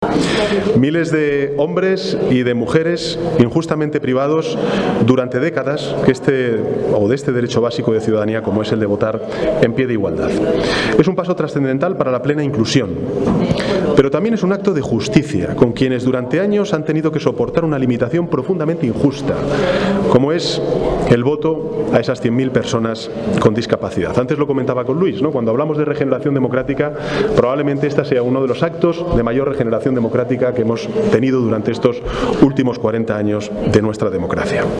Arriba discurso del presidente, Pedro Sánchez.